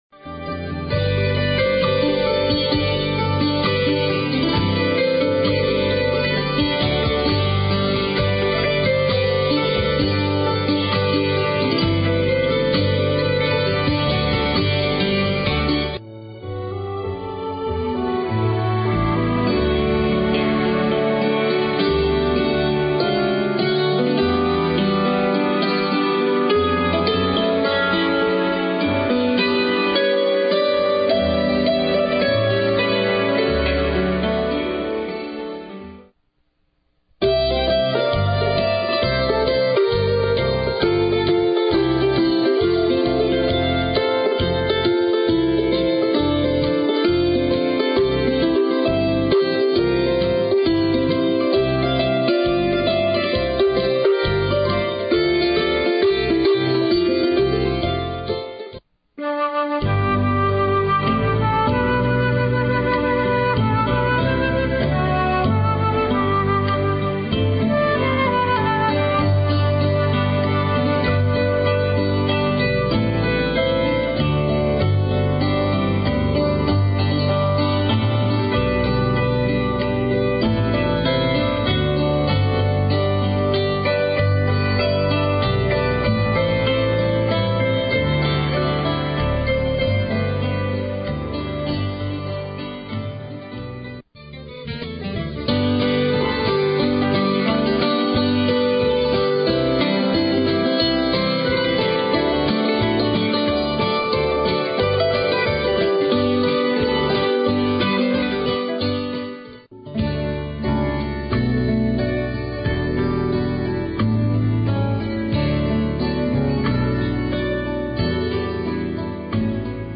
Hammer Dulcimer
Instrumental.